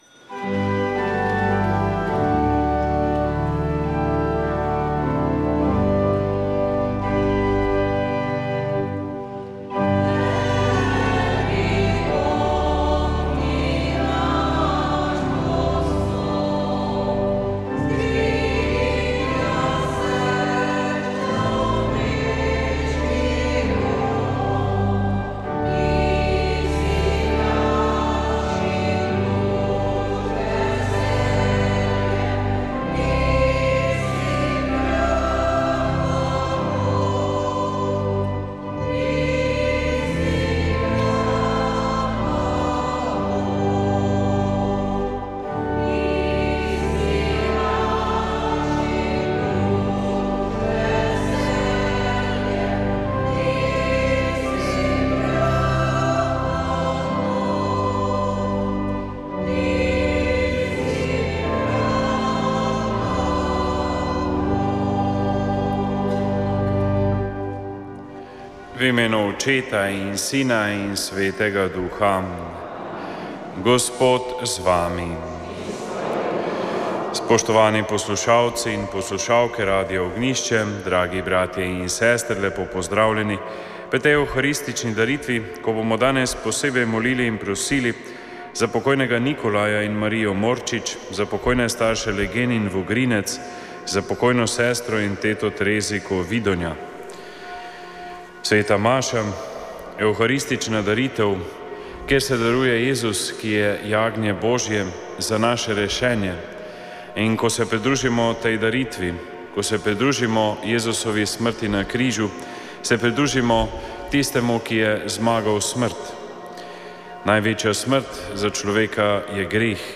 Sv. maša iz stolne cerkve sv. Nikolaja v Murski Soboti 17. 7.